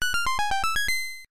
Sound effect from Super Mario RPG: Legend of the Seven Stars
Self-recorded using the debug menu
SMRPG_SFX_Link_Speak.mp3